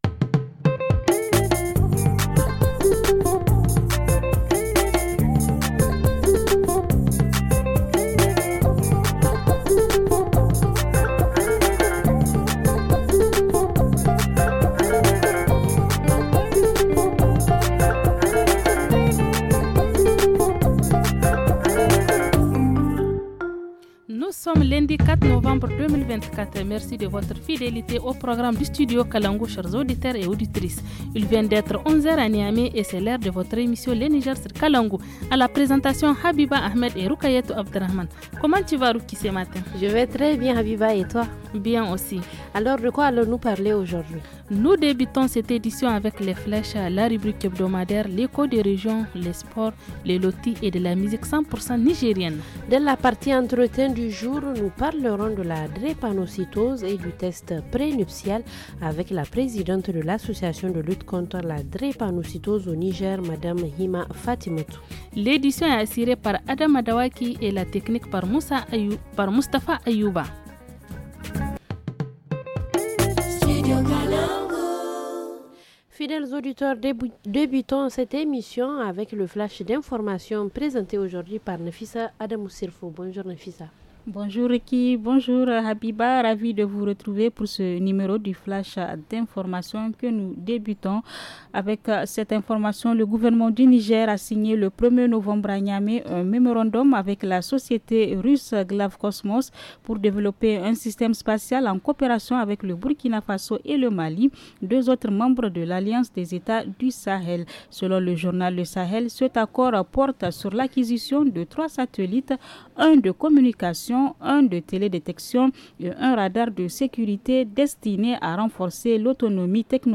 Le reportage région, fait le point sur la collecte de sang organisée par un groupe de jeunes de Tessaoua.